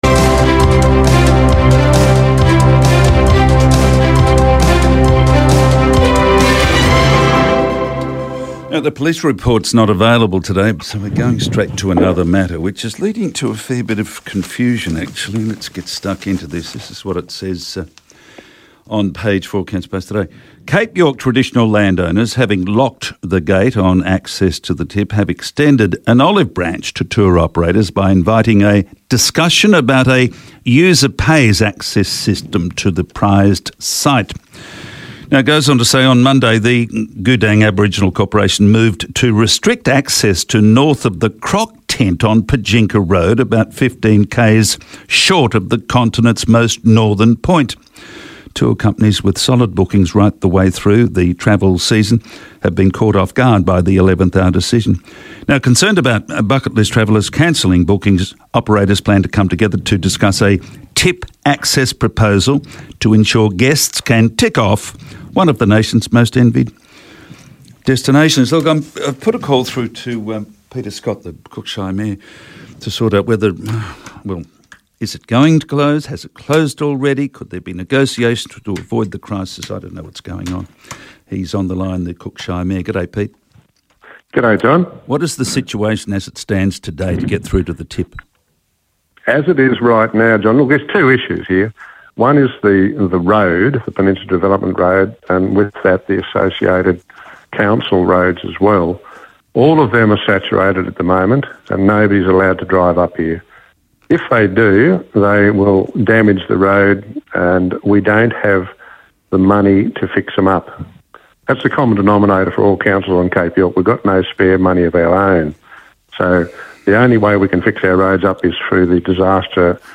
Today I spoke to Peter Scott, the Mayor of Cook Shire, about the Tip and issues surrounding tourists and operators.